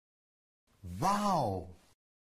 wow-mlg-sound-effect-hd_7OvkYJj.mp3